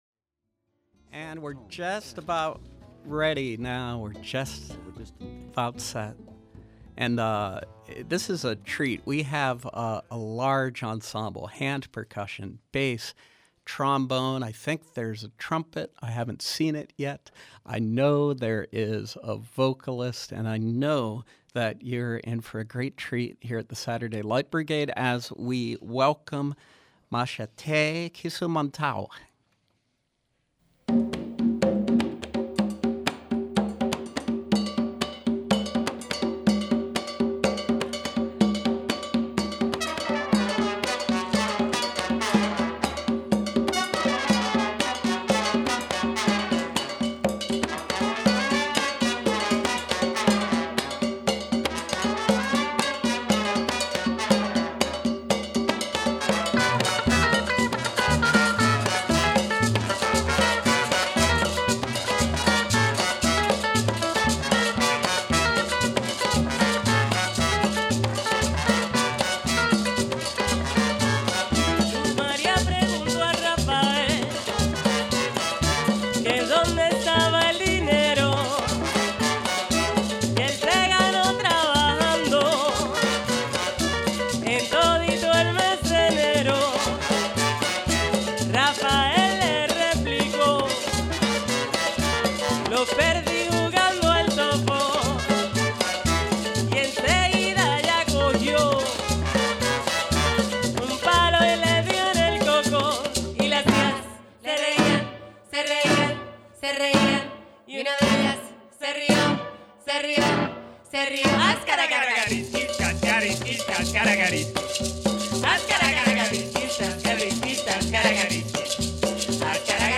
Afro-Caribbean music
a 7-piece Pittsburgh-based ensemble